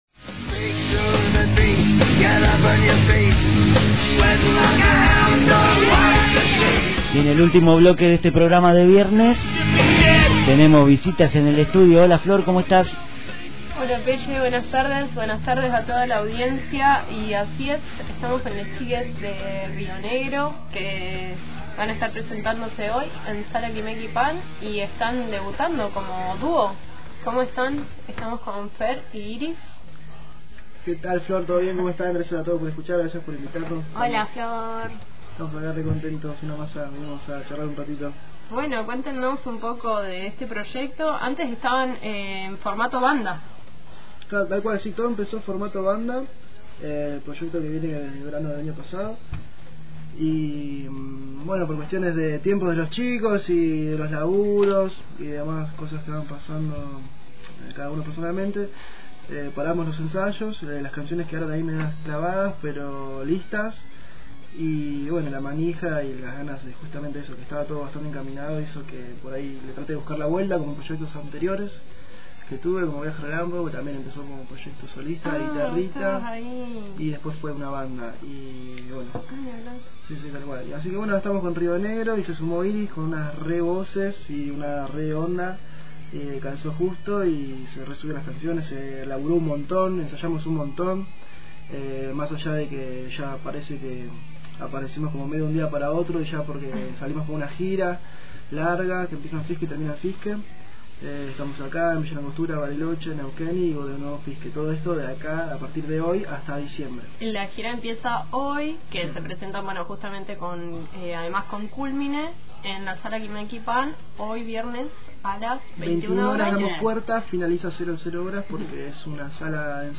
Escuchá la entrevista con el dúo «Río Negro» aquí debajo: